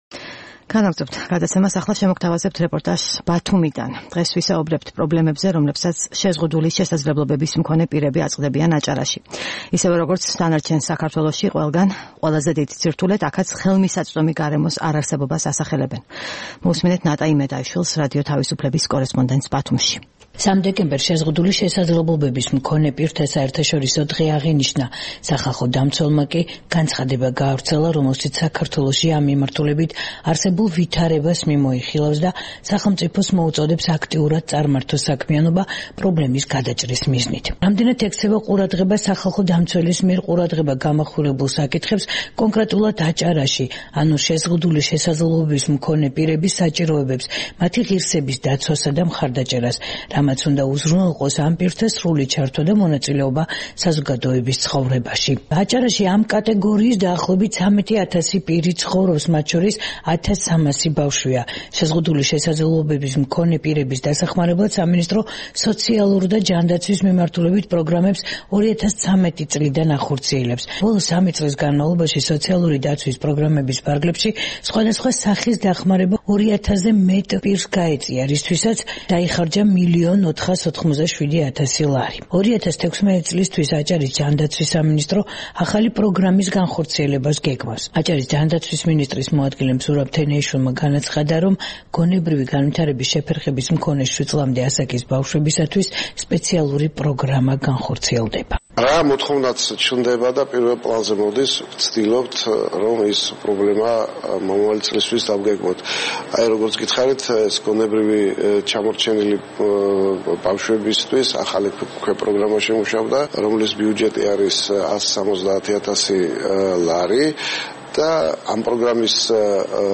რეპორტაჟი ბათუმიდან